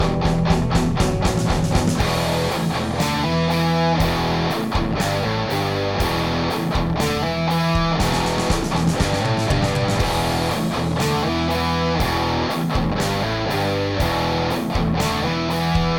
Guys, made a slight tone tweak to try and kill that nasty mid and put a little more meat on the bones... Only about a 20s clip, it doesn't even sound that different - just has more meat and slightly less harshness "in the mids" (In the mids?